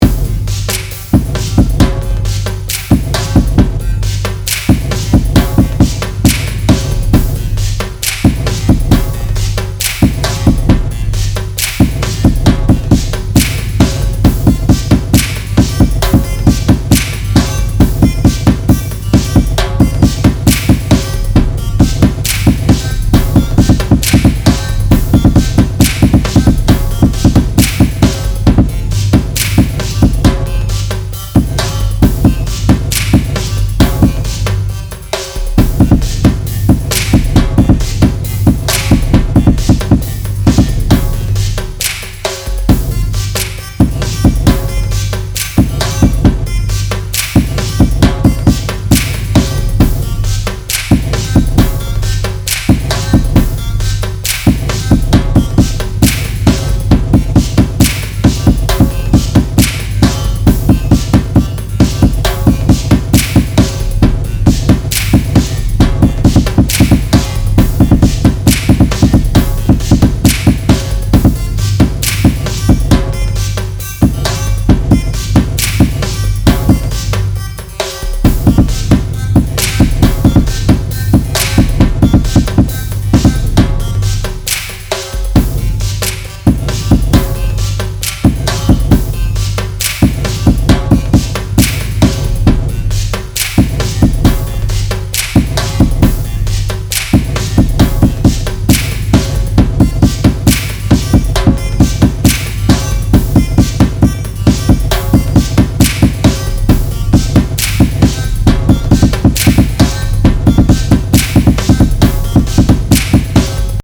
Ultra-drum-groove-with-api.mp3